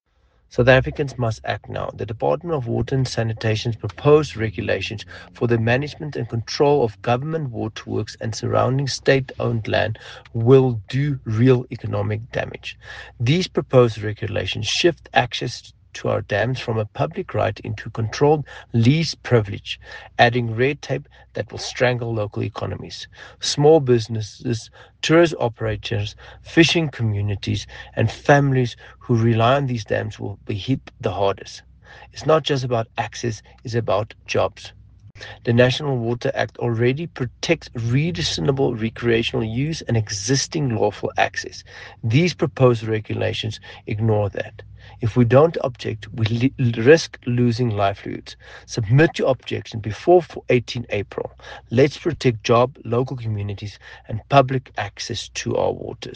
English and Afrikaans soundbites by Dr Igor Scheurkogel MP.